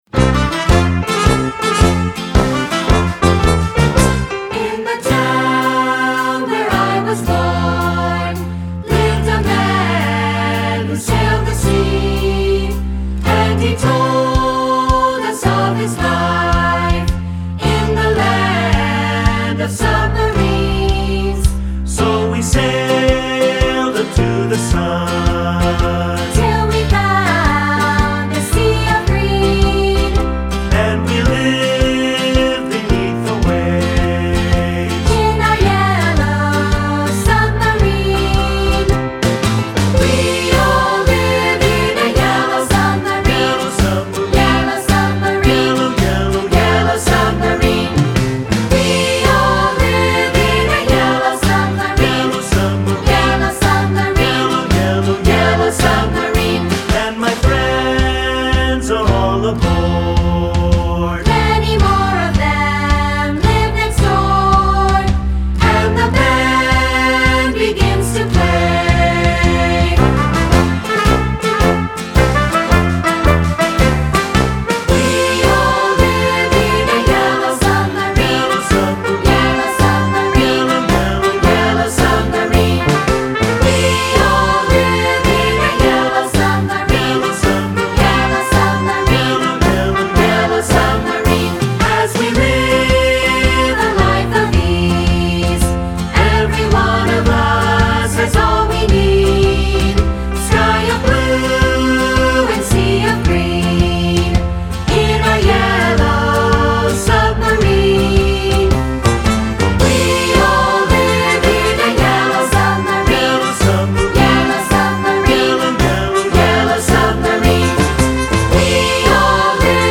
Choral 50's and 60's Pop
3 Part Mix
3-Part Mixed Audio